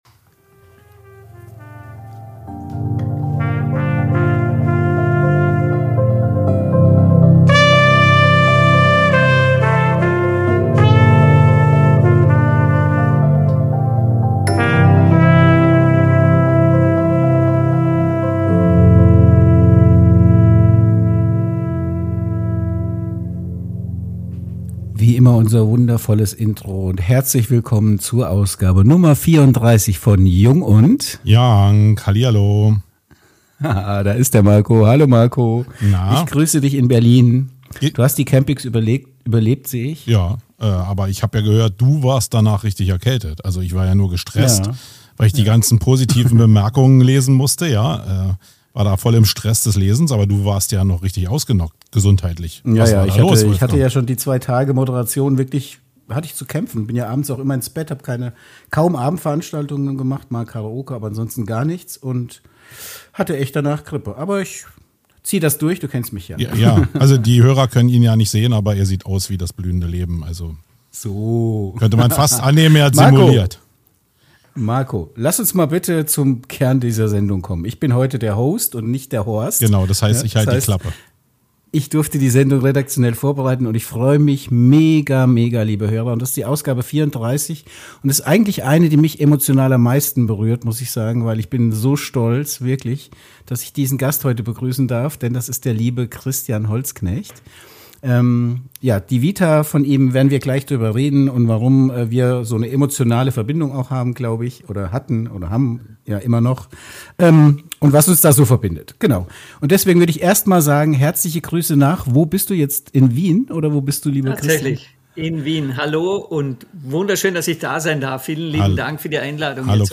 Ein wirklich interessanter Talk mit vielen kontroverse Sichtweisen und Standpunkten.